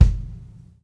kick 5.wav